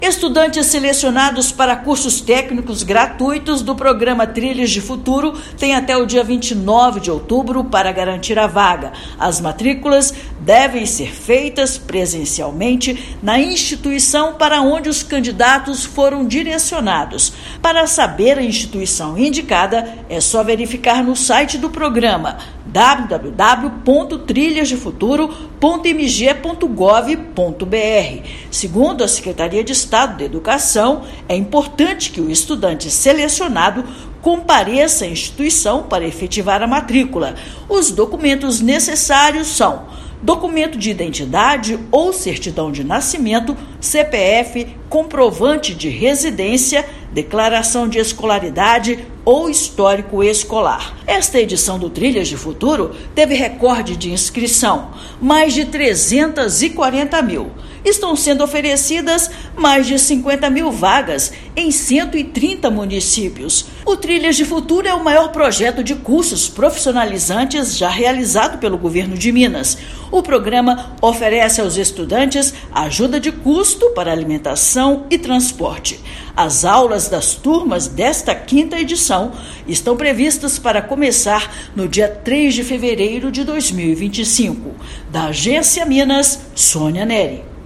Somente após a matrícula o estudante terá a vaga garantida; prazo vai até 29/10. Ouça matéria de rádio.